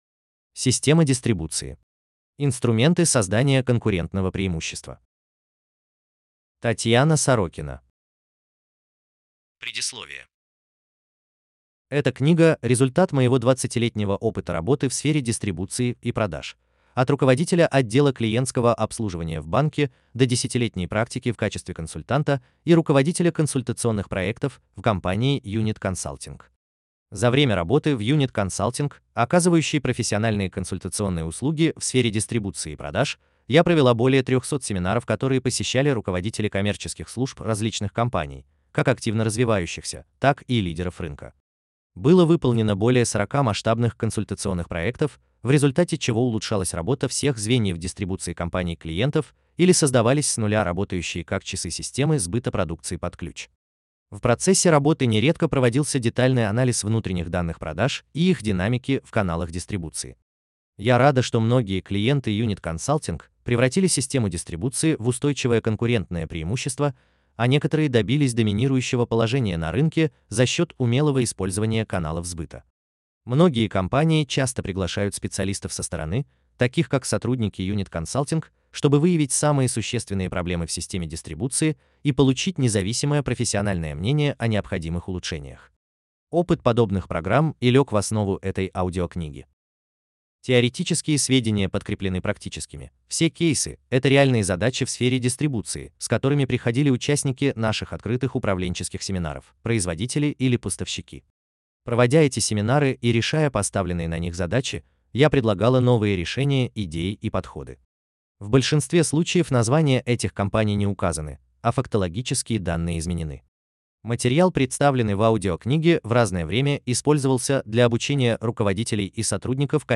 Аудиокнига Система дистрибуции. Инструменты создания конкурентного преимущества | Библиотека аудиокниг